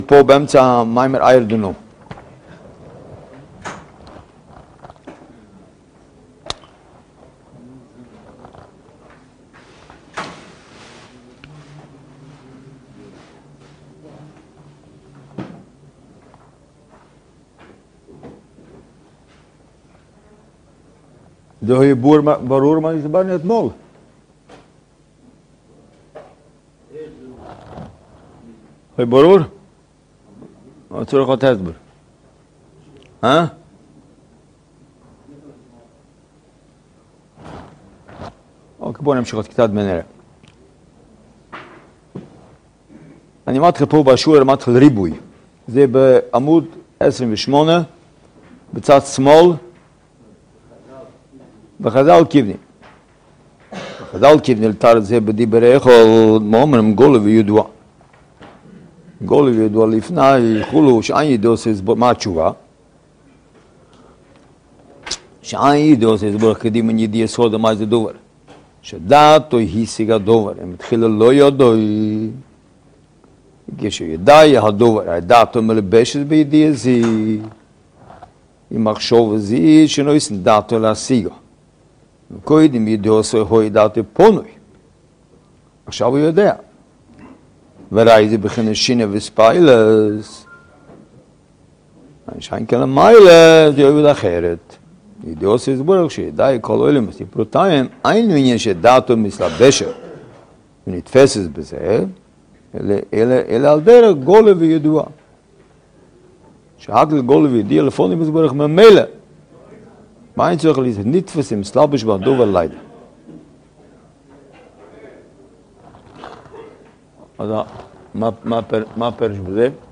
שיעור יומי